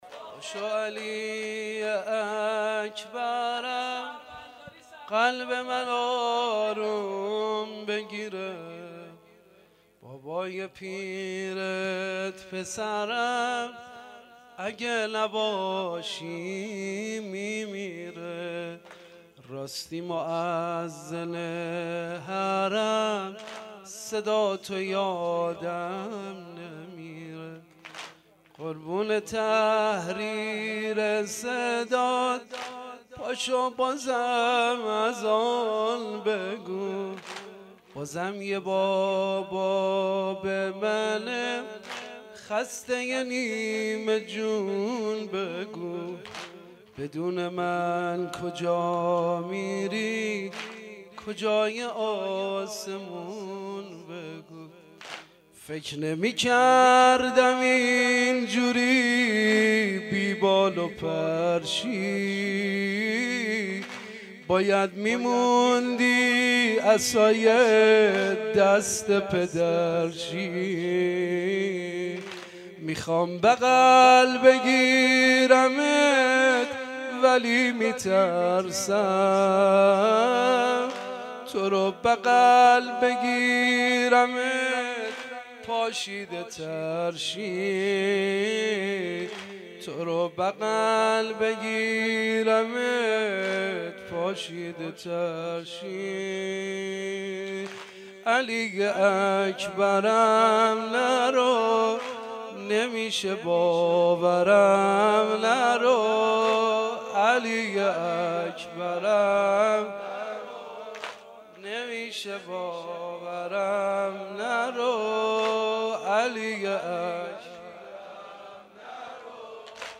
4واحدسنگین-دیدار-شب-8-محرم-98.mp3